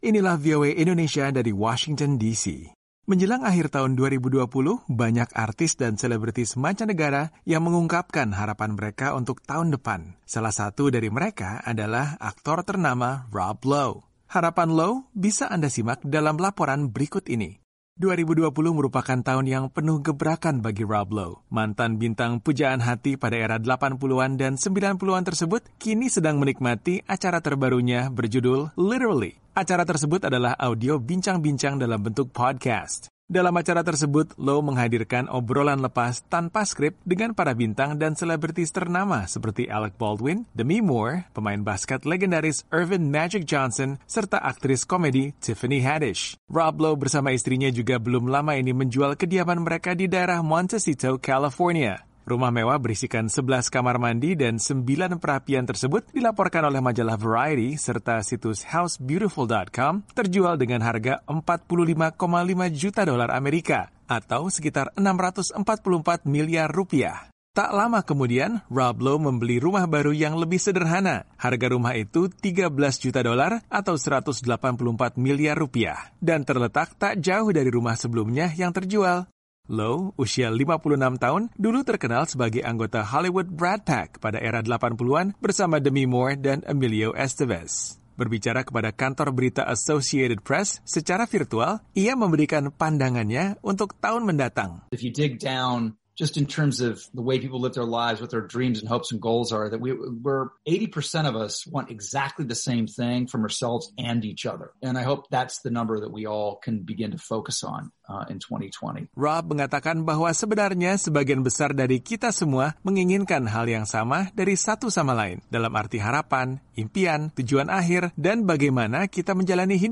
Harapannya untuk tahun 2021 bisa Anda simak dalam laporan berikut ini.